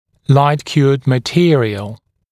[laɪt-kjuəd mə’tɪərɪəl][лайт-‘кйуэд мэ’тиэриэл]светоотверждаемый материал